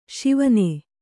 ♪ Śivane